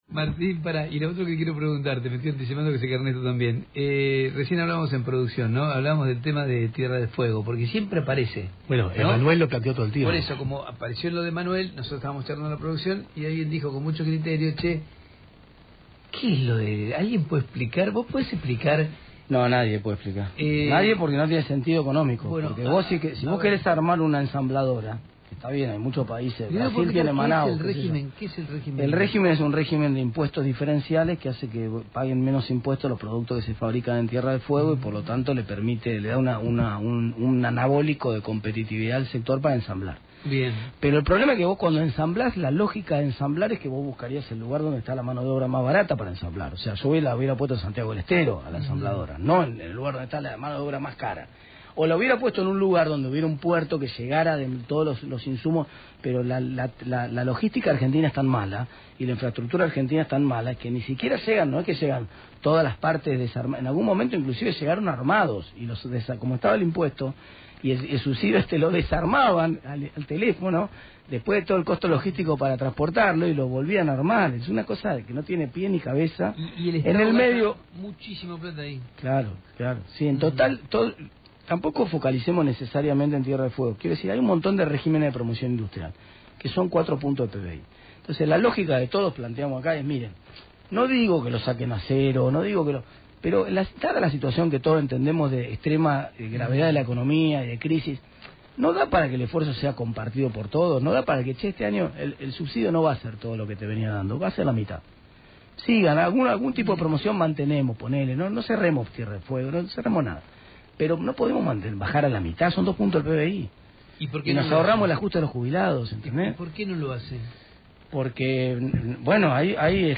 También publicamos el audio de Martin Tetaz, Diputado nacional de la UCR proponiendo que se quite el 50 % del subregimen, o se lleve a Santiago del Estero y desconociendo la existencia del puerto de Ushuaia.
Aqui otro audio de Martin Tetaz, Diputado de la UCR , hablando de lo que no conoce, de lo que jamás visito, y aun asi, miente descaradamente, desconoce la existencia del puerto en Ushuaia, propone recortar el 50 % lo que debe enviar desde naciona y también dice que son 2 puntos del PBI, cuando en realidad es un 0,33 %, es realmente insoportable esta gente que ni siquiera pueden disimular su ignorancia. las declaraciones se dieron en el programa de Tenembaum en Radio con vos.